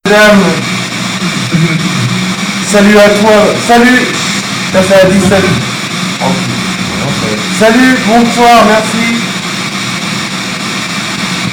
014 salut à  toi voix ralenti.mp3
014-salut-a-toi-voix-ralenti.mp3